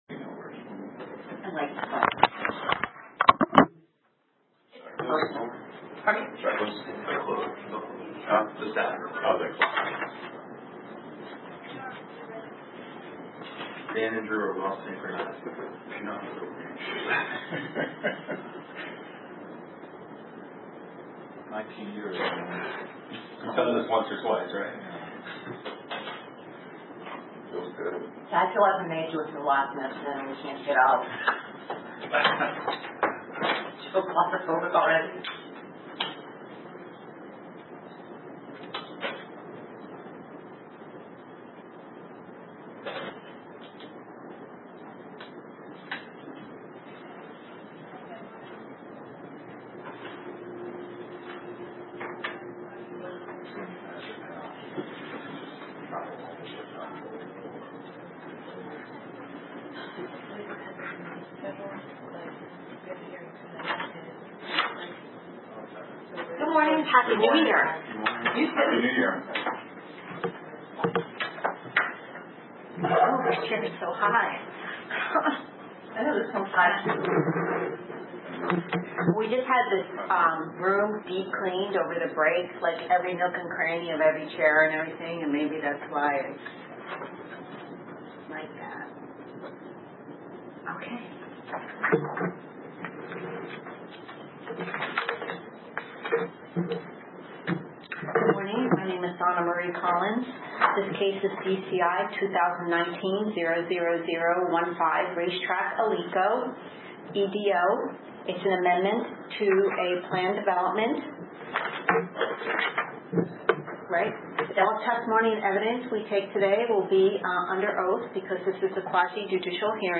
RECORDING OF THE JANUARY 9, 2020 HEARING FOR RACETRAC ALICO EDO